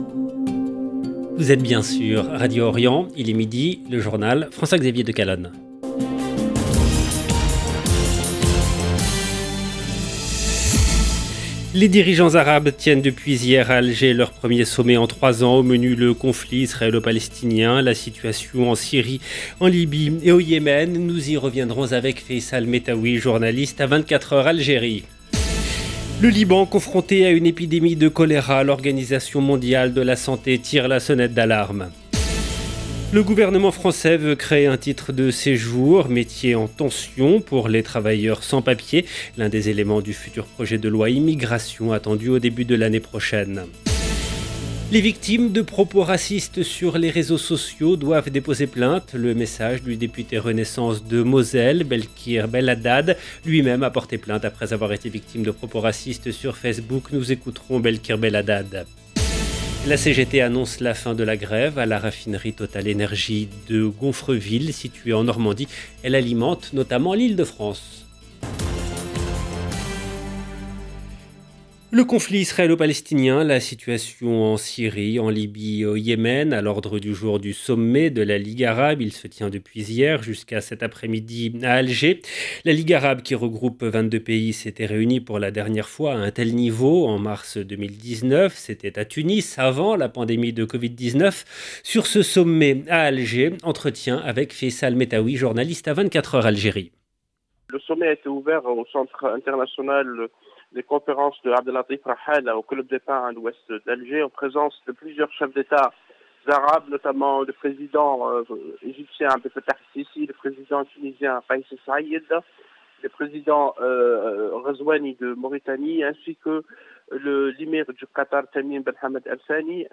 EDITION DU JOURNAL DE 12 H EN LANGUE FRANCAISE DU 2/11/2022